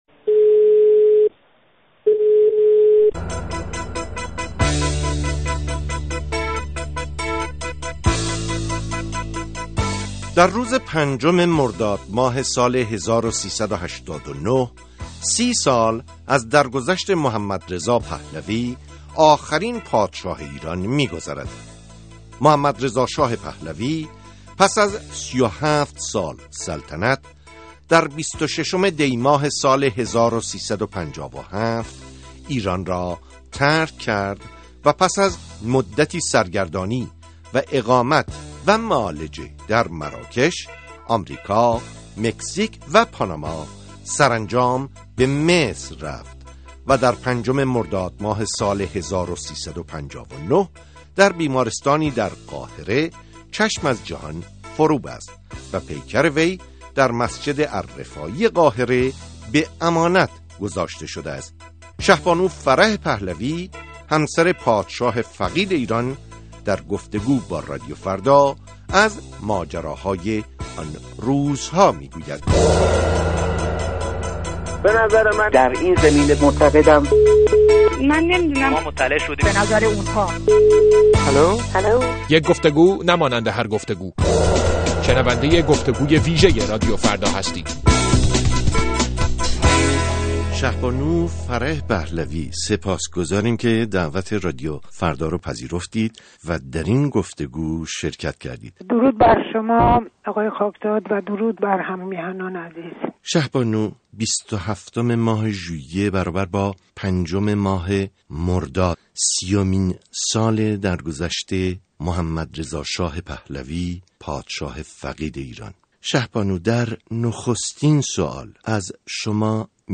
سى سال پس از درگذشت آخرين شاه ايران؛ مصاحبه با فرح پهلوى